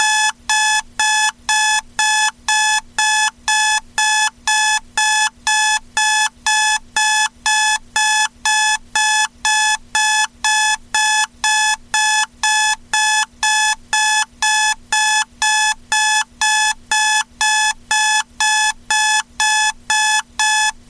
CRACK